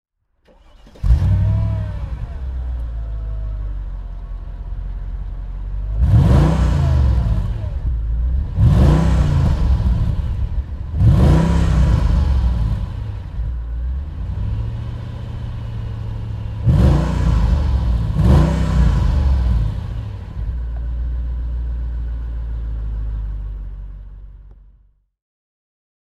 Mercedes-Benz SL 55 AMG (2002) - Starten und Leerlauf
Mercedes_SL_55_AMG.mp3